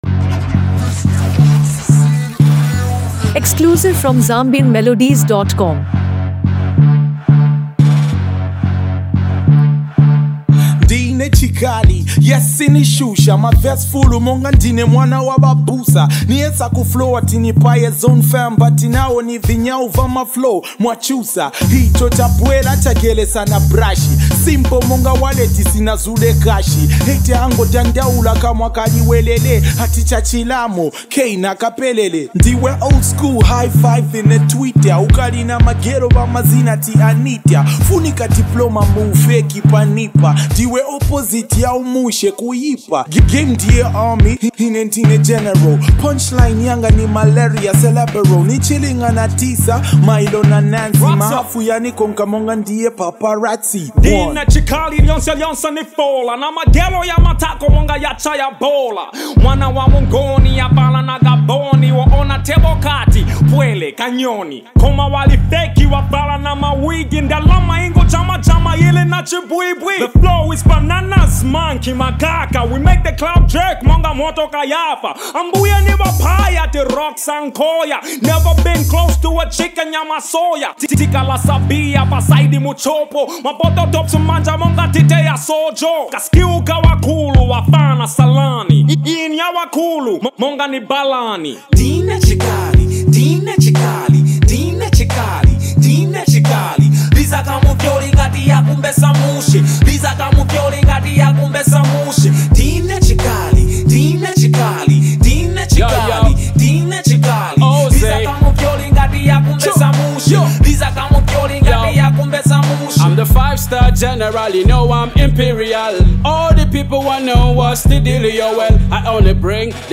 vibrant Zambian hip-hop and Afro-fusion energy
bold rap verses
catchy hook, hard-hitting verses, and energetic production